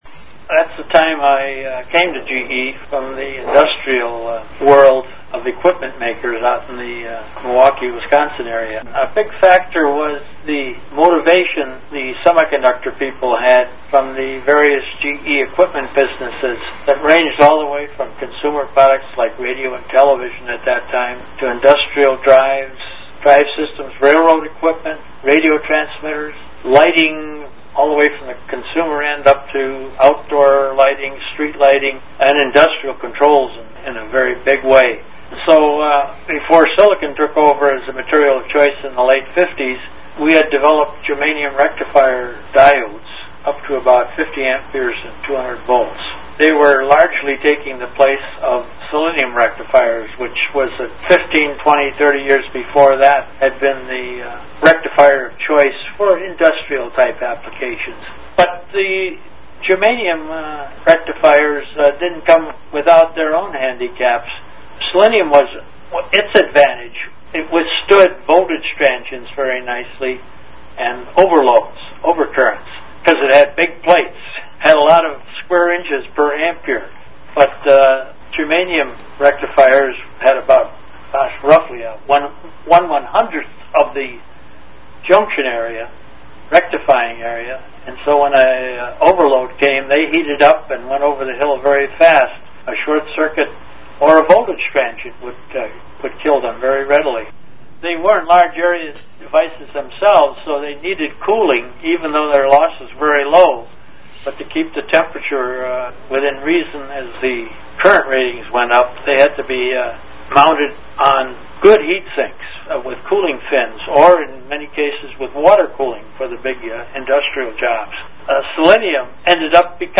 from a 2005 Interview with